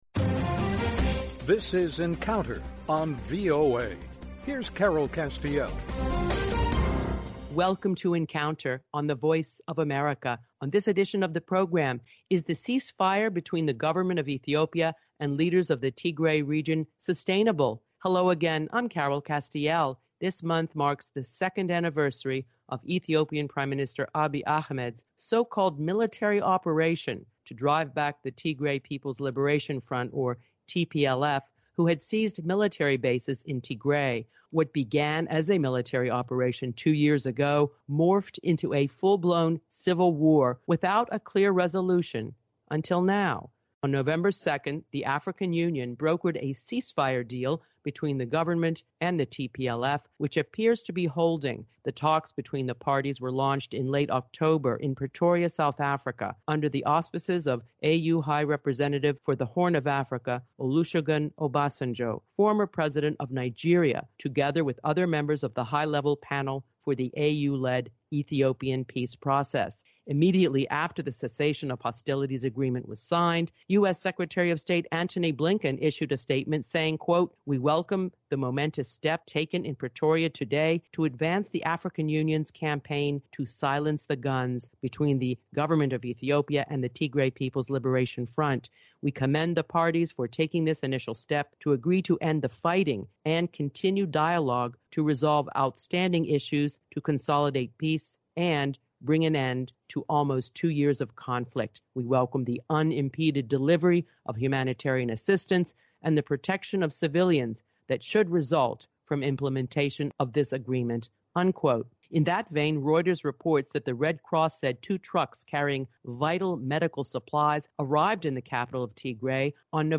On November 2, 2022, the Ethiopian government and leaders of the Tigray Region, who have been fighting for two years, signed a ceasefire agreement brokered by the African Union. Ambassador Tibor Nagy, former assistant secretary of state for African Affairs and Donald Booth, former Ambassador to Ethiopia and special envoy to Sudan and South Sudan, discuss the significance of this critical step toward enduring peace and the promises and perils ahead in its implementation